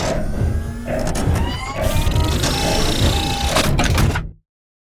repair.wav